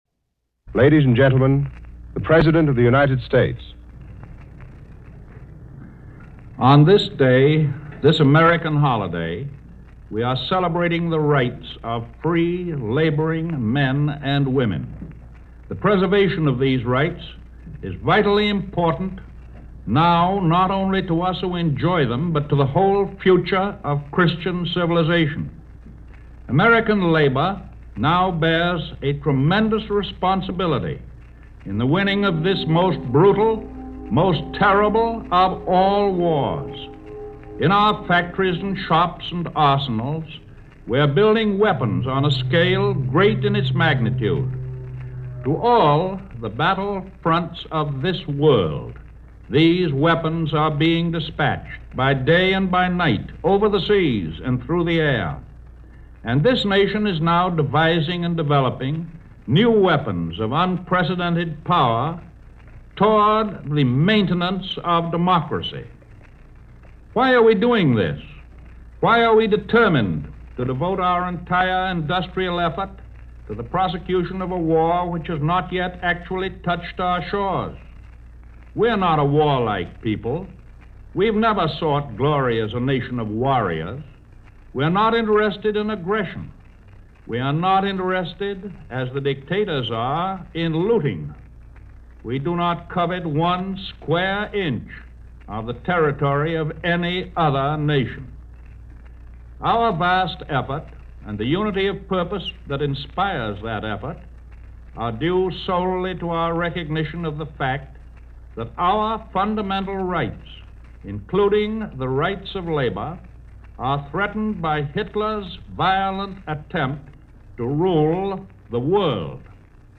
President Roosevelt delivered his annual address in 1941 with a sense of the inevitable, but a sense of determination that the Axis powers would not win the war: